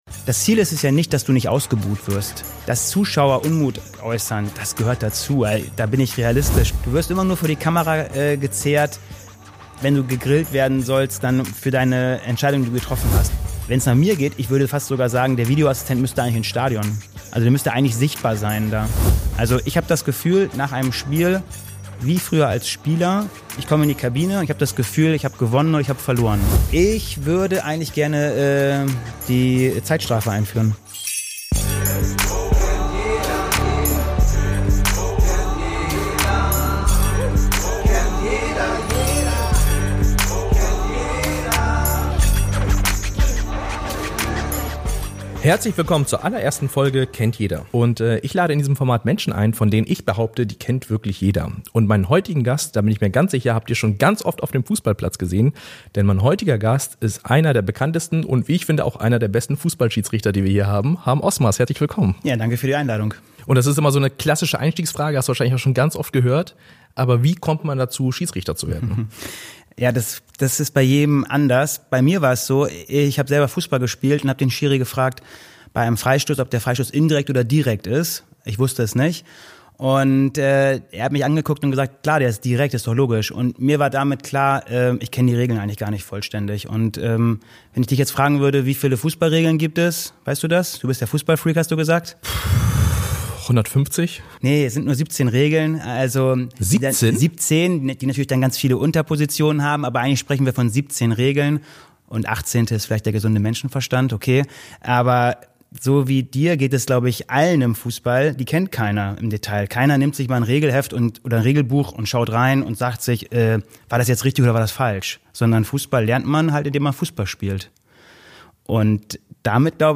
In dieser ersten spannenden Episode begrüße ich den Bundesliga- und FIFA-Schiedsrichter Harm Osmers. Gemeinsam werfen wir einen Blick hinter die Kulissen: Wie läuft eigentlich ein Fußballspiel für einen Schiedsrichter ab?